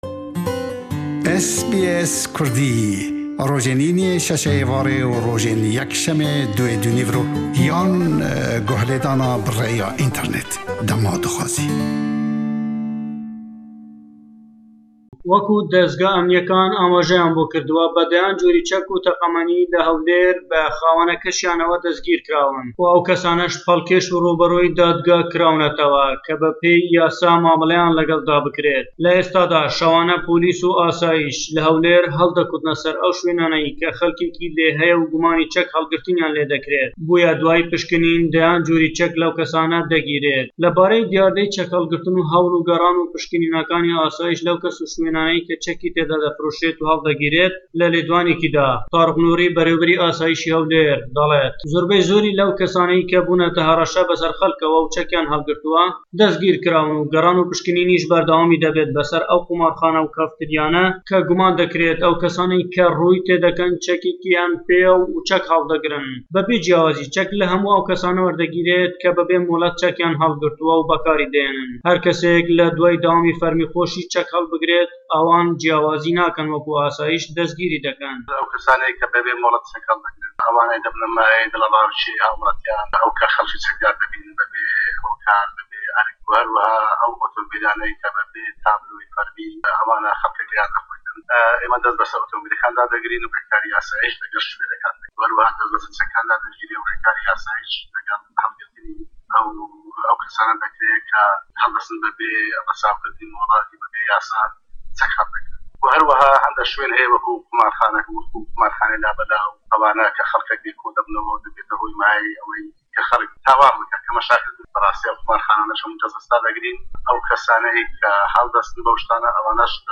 Le em raportey peyamnêrman